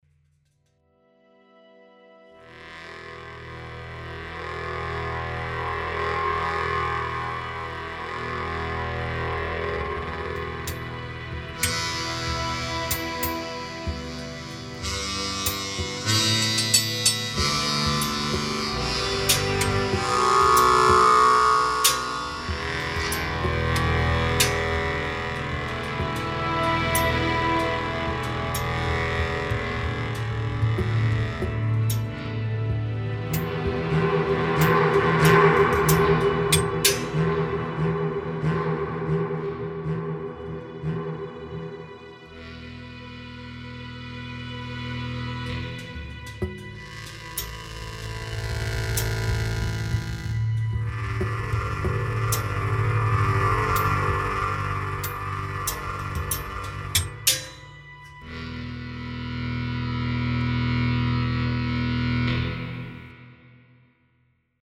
BEAUTIFULLY ODD